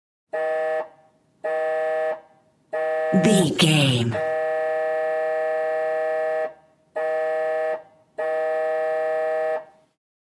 Ambulance Int Horn
Sound Effects
urban
chaotic
anxious